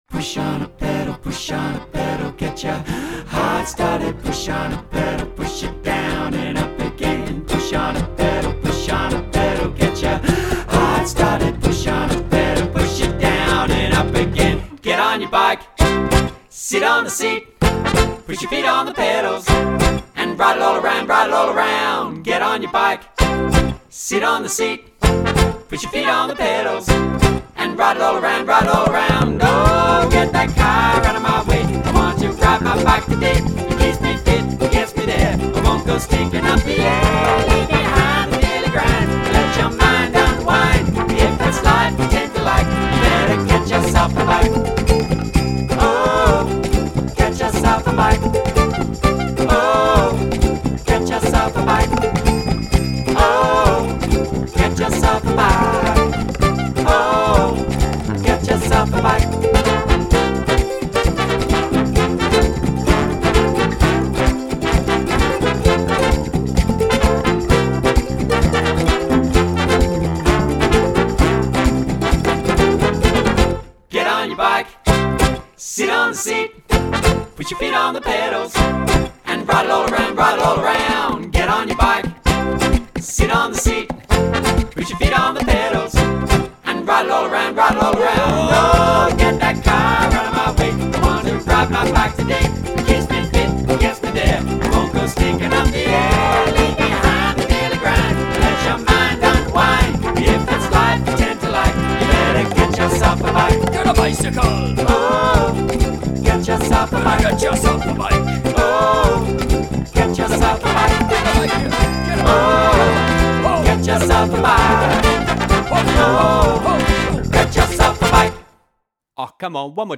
mp3 of my latest recording the kids version, (there's a